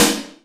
SNARE 005.wav